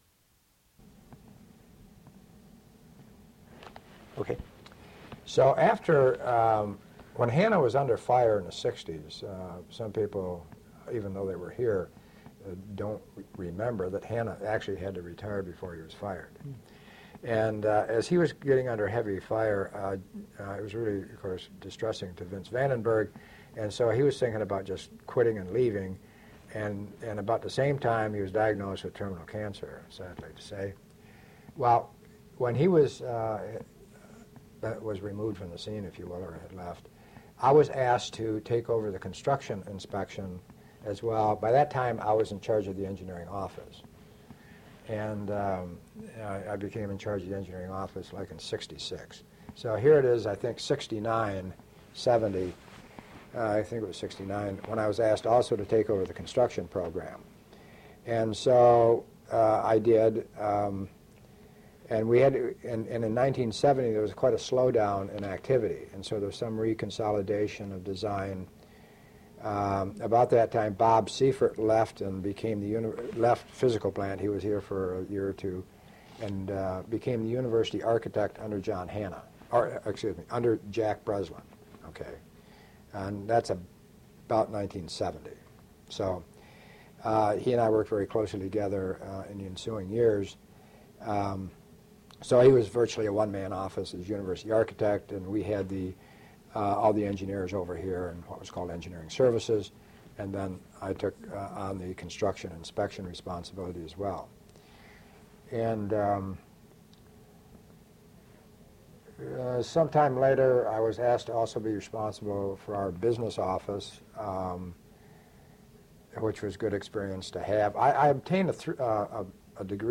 Original Format: Audiocassettes
Sesquicentennial Oral History Project